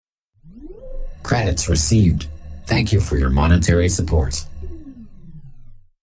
tip-credits-recieved-male-spark-grateful.mp3